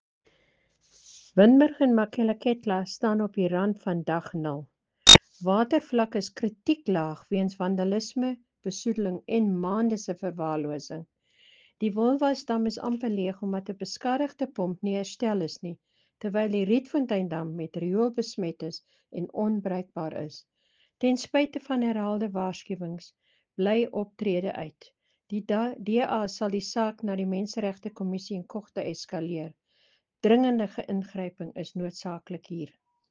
Afrikaans soundbites by Cllr Brunhilde Rossouw and